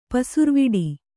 ♪ pasurviḍi